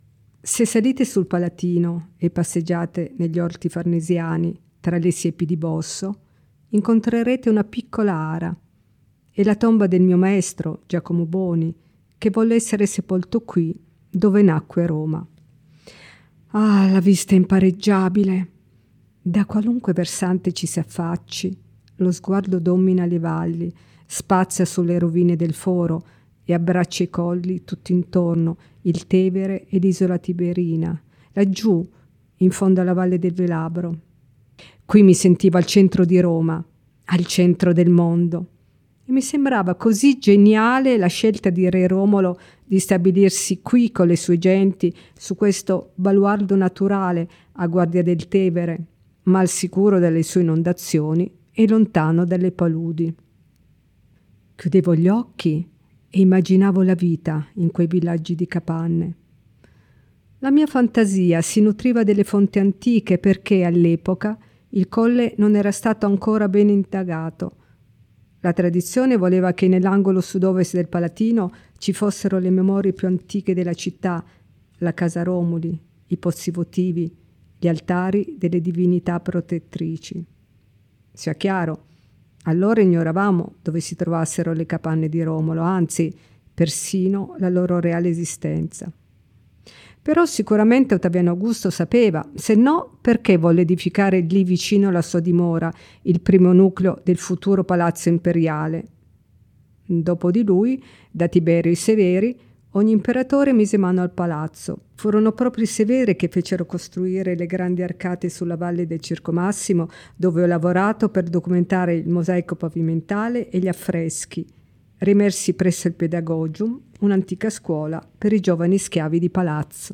• approfondimenti audio, con il racconto in prima persona di Maria Barosso e le audiodescrizioni delle opere selezionate
Storytelling Maria Barosso: